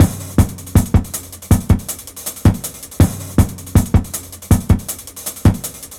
Index of /90_sSampleCDs/Zero-G - Total Drum Bass/Drumloops - 1/track 08 (160bpm)